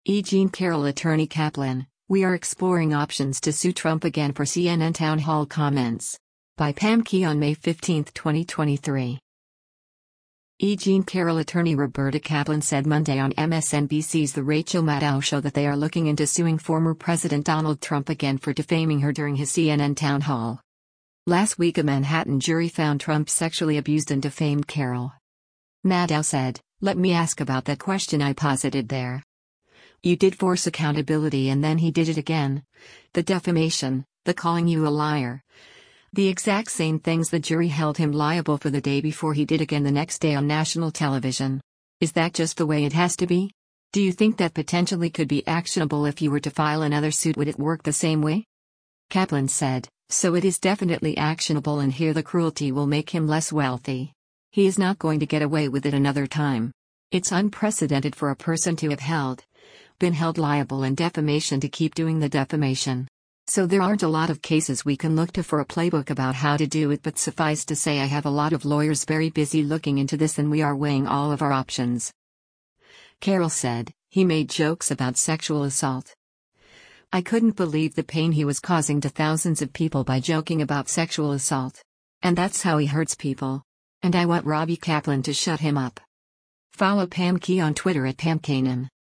E. Jean Carroll attorney Roberta Kaplan said Monday on MSNBC’s “The Rachel Maddow Show” that they are looking into suing former President Donald Trump again for defaming her during his CNN town hall.